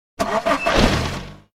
KART_Engine_start_1.ogg